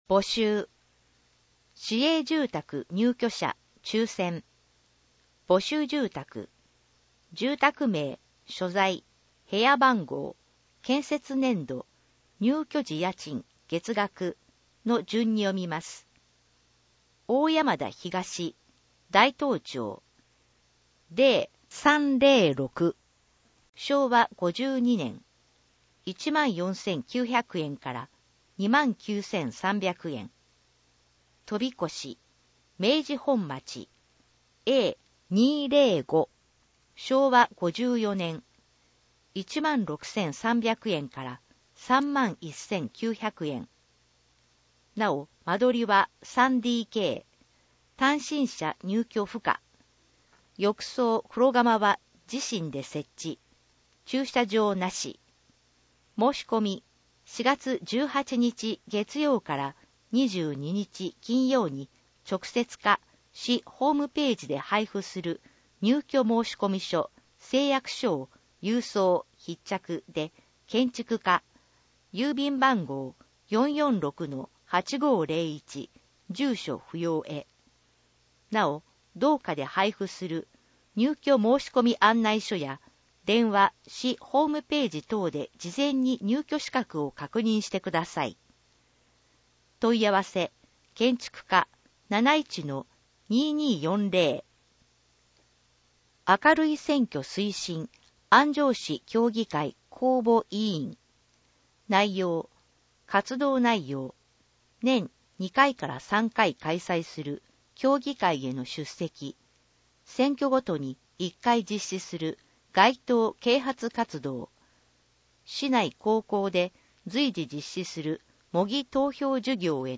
以上の音声データは、「音訳ボランティア安城ひびきの会」の協力で作成しています